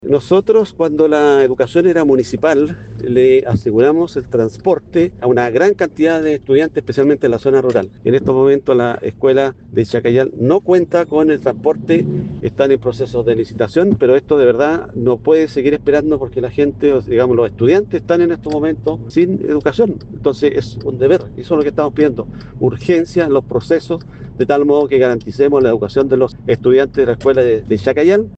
Así lo explicó el edil de Santa Juana, Ángel Castro, quien pidió celeridad al SLEP Andalién Costa.